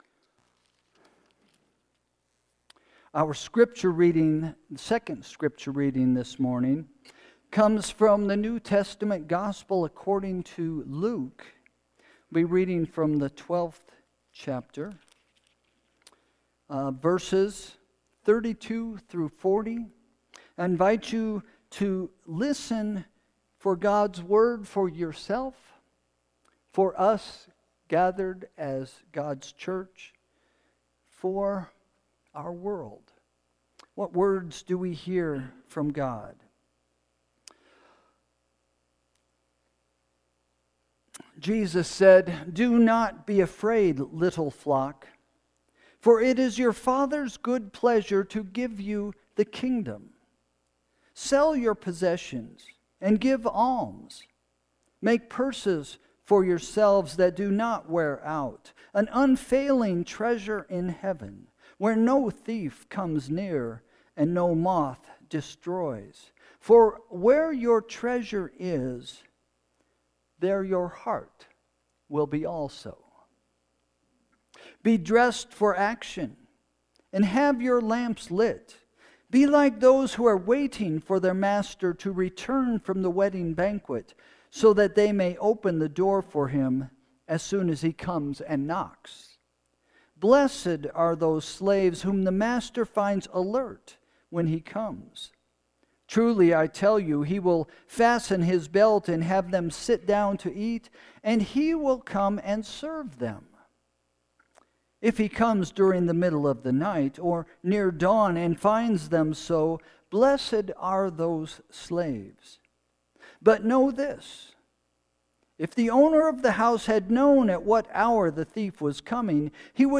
Sermon – July 27, 2025 – “Faith – A Sure Thing” – First Christian Church
Sermon – July 27, 2025 – “Faith – A Sure Thing”